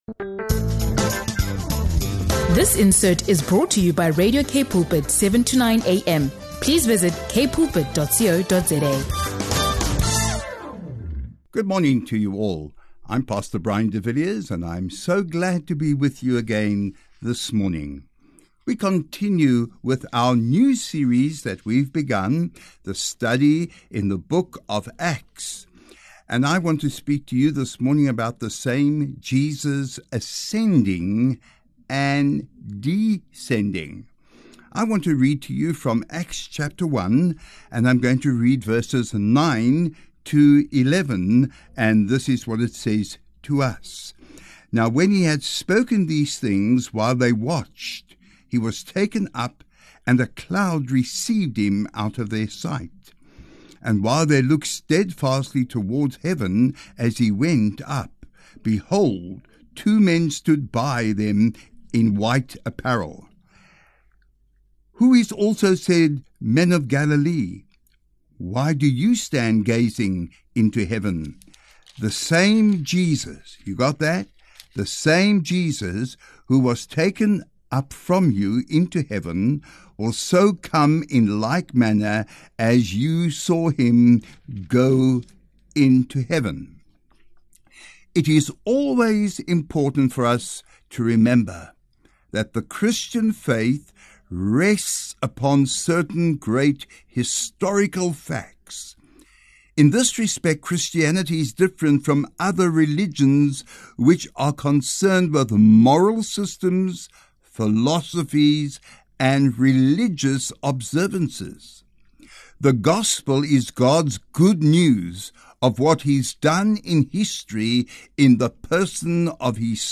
In this powerful Bible teaching from Acts 1:9–11